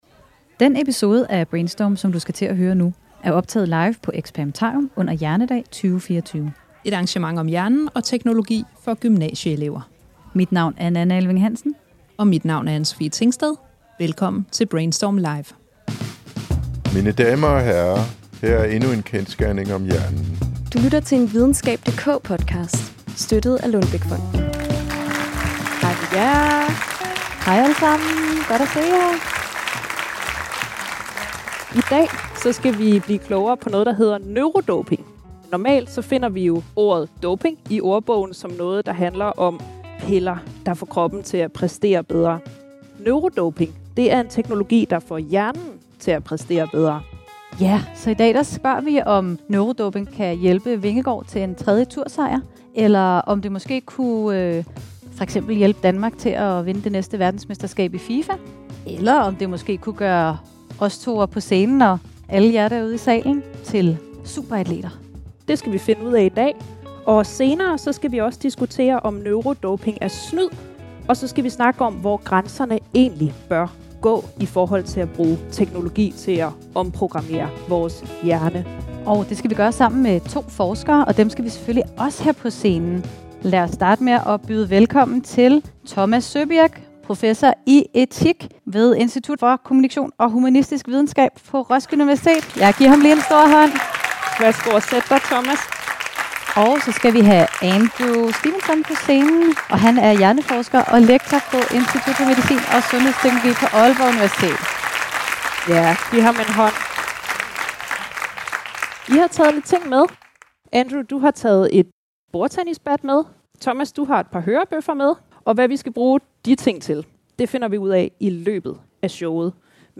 Få svar på det og meget mere i denne episode af Brainstorm, der er optaget live på Experimentarium under arrangementet Hjernedag 2024.